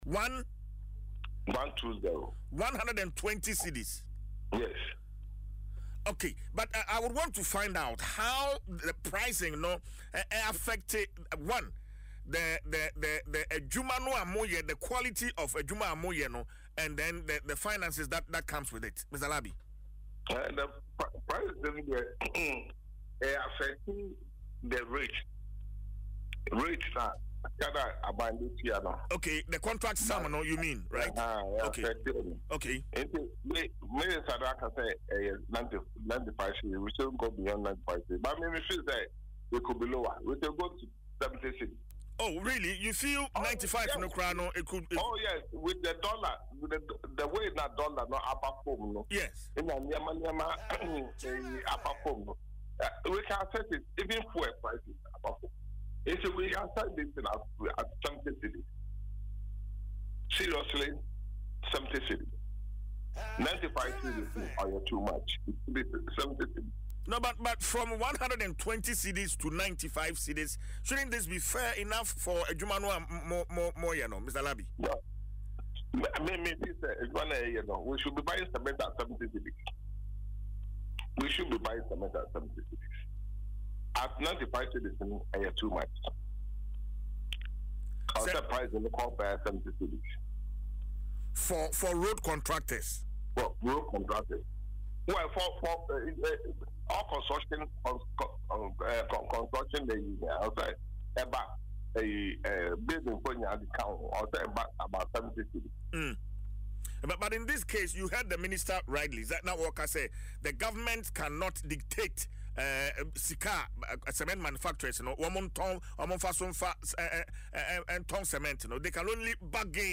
Hot AudioNewsLocal News